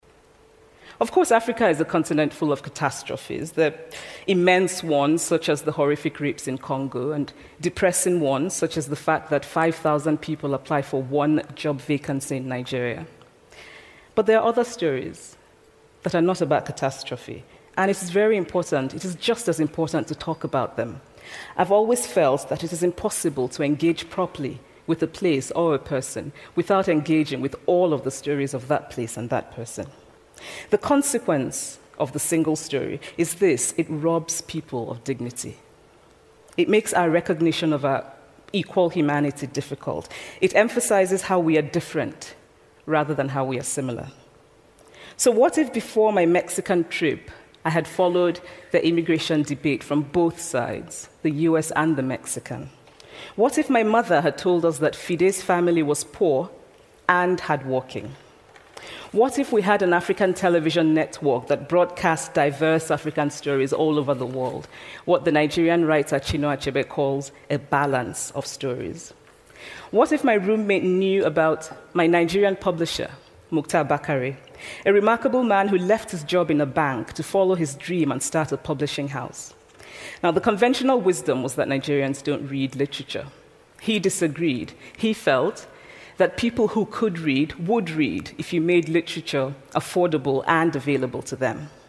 TED演讲：单一故事的危险性(9) 听力文件下载—在线英语听力室